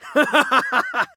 PLAY HAHAHAHAHA ( RISADA SIEGHART)
hahahahahaha-risada-sieghart_uw3ok3q.mp3